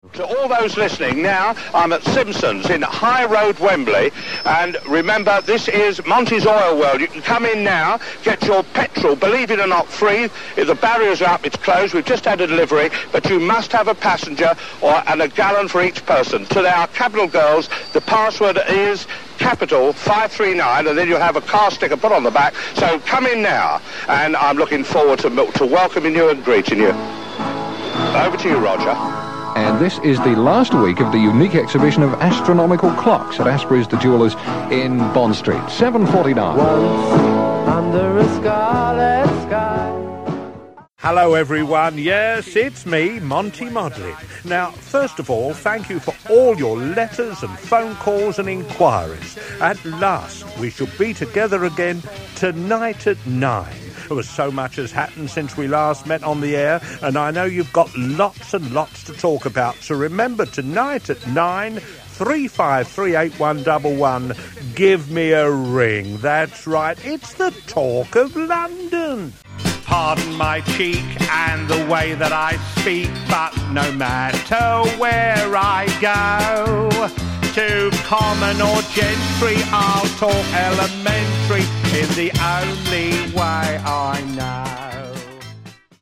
This cheeky Cockney roving reporter began his media career in press, before making his debut on Forces radio.